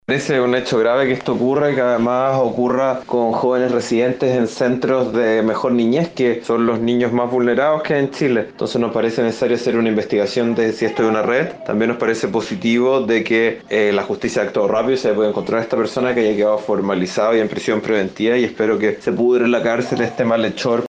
El diputado del Partido Socialista, Tomás de Rementería, valoró la rapidez del procedimiento y aseguró que “parece necesario hacer una investigación de si esto es una red”.